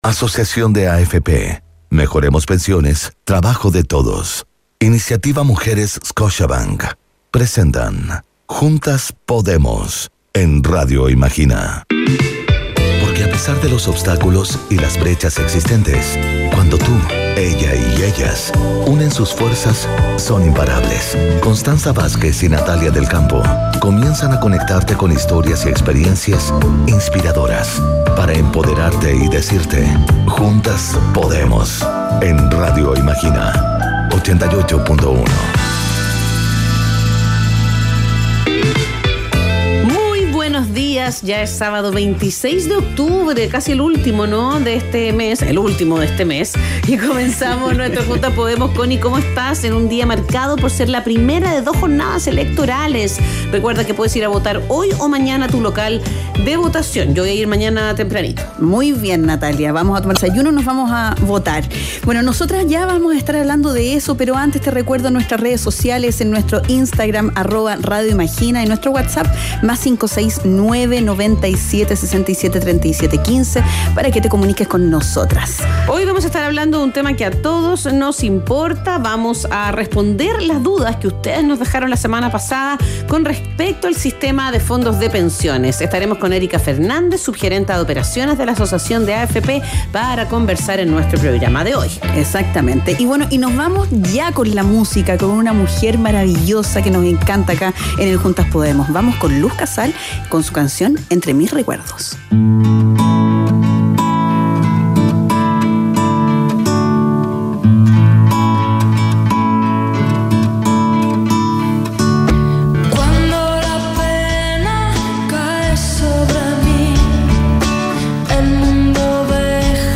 Ejecutiva de la asociación de AFP responde preguntas de auditores de Radio Imagina sobre educación previsional - Asociación de AFP de Chile